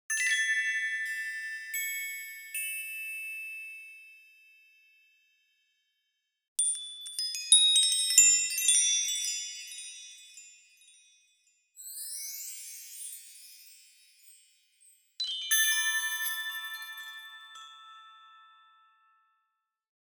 zachte feestelijke klanken
• Geluid: 15 - 20 feestelijke kerstdeuntjes